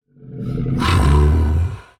Minecraft Version Minecraft Version snapshot Latest Release | Latest Snapshot snapshot / assets / minecraft / sounds / mob / ravager / idle7.ogg Compare With Compare With Latest Release | Latest Snapshot